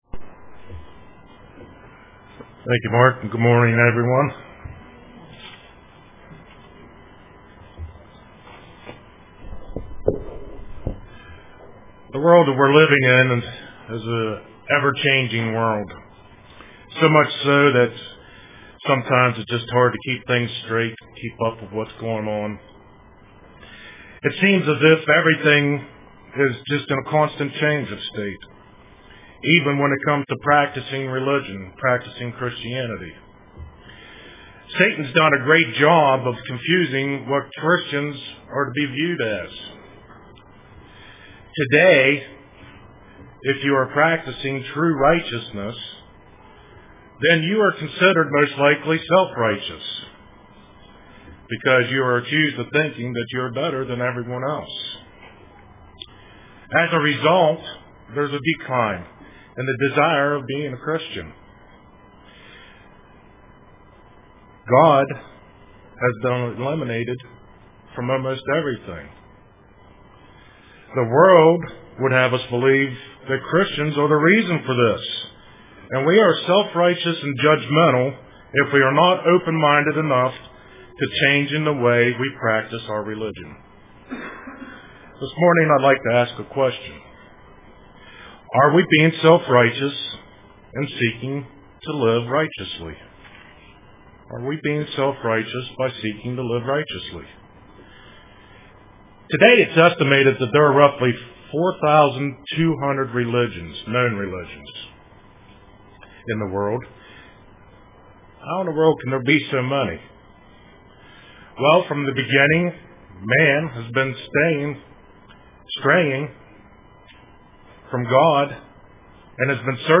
Print Striving for Righteousness UCG Sermon Studying the bible?